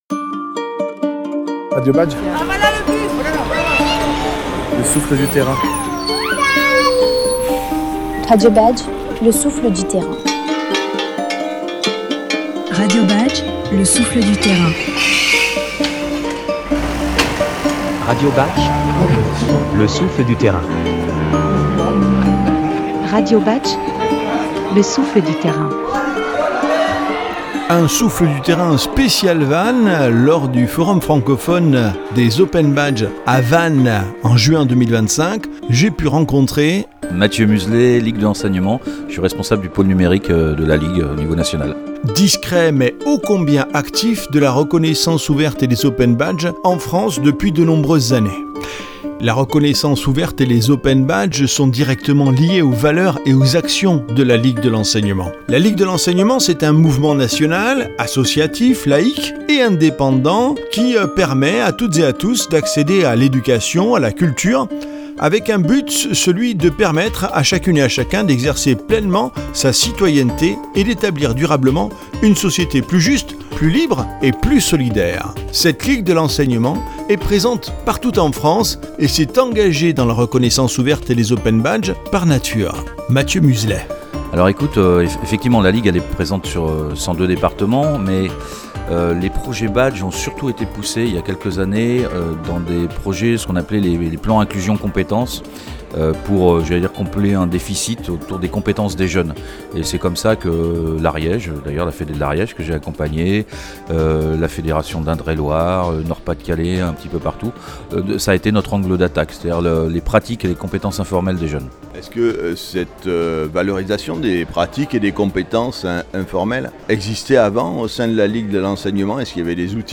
Rencontre à Vannes